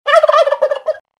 смешные
звуки животных